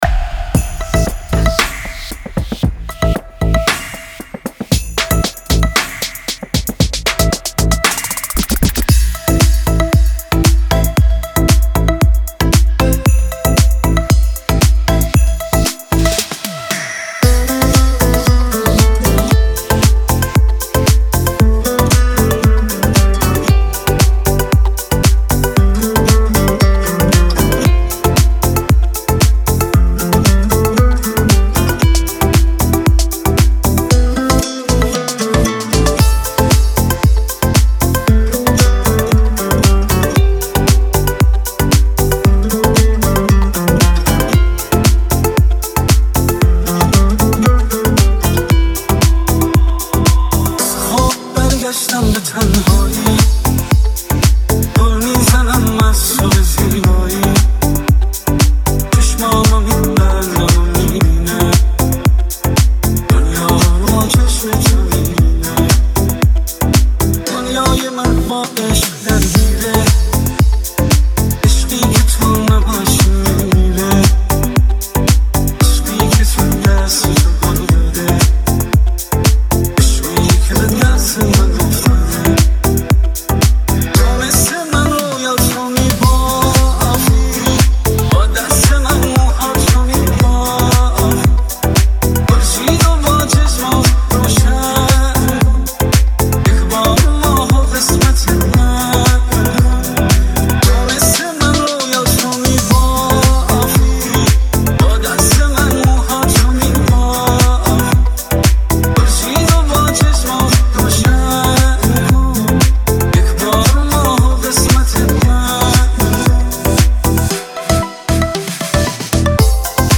+ ریمیکس و ورژن گیتار آکوستیک آرام اضافه شد
دانلود ورژن گیتار